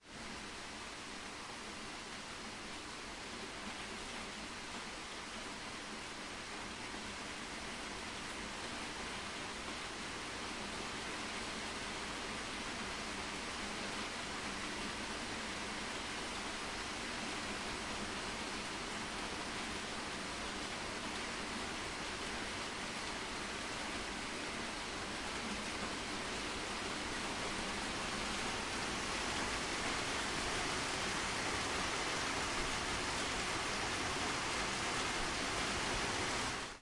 Ambience » AC130 Internal Sound (speech + drone)
描述：Layered sounds from my collection from the drone of the propellers and real speech cut up from real ac130 session. included noise for realism
标签： internal ac130 plane insideplane inside from sound
声道立体声